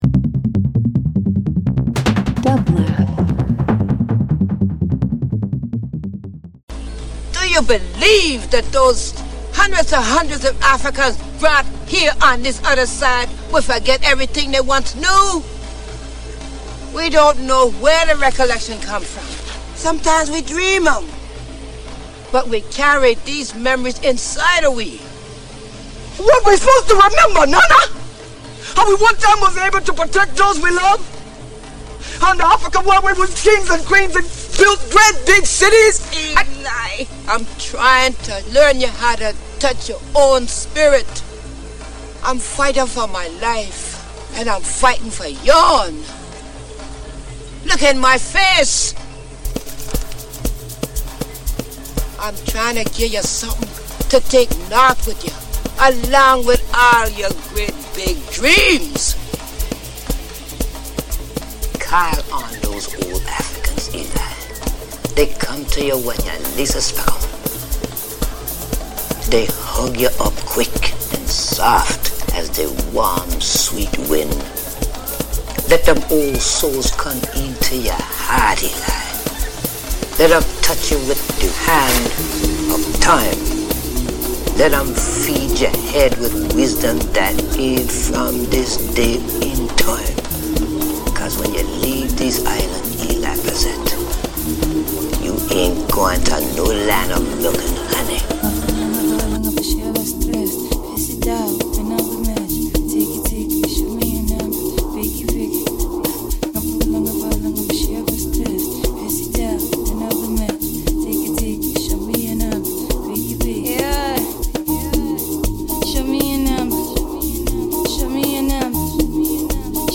African Amapiano Dance House